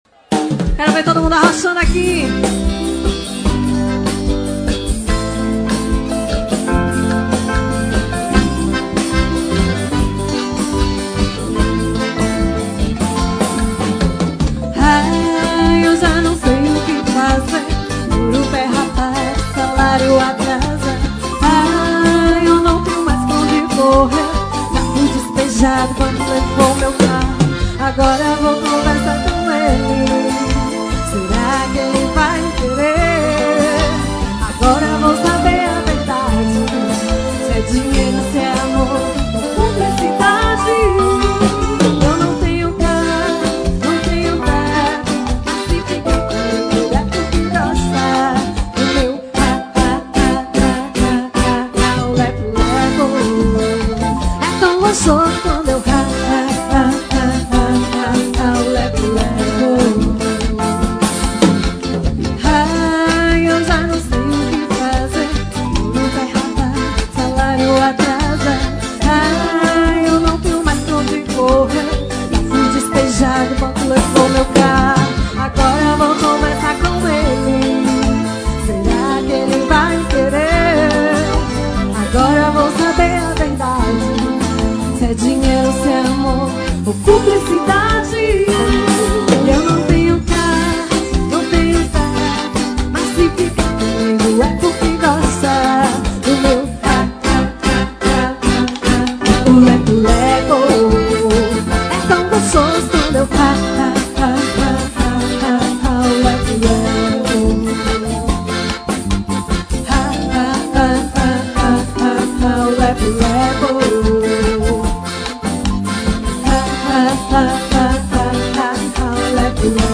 Show ao vivo